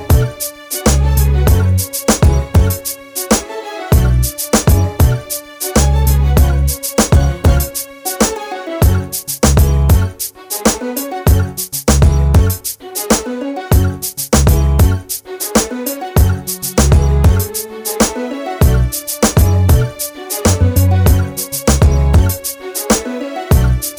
no Backing Vocals R'n'B / Hip Hop 3:37 Buy £1.50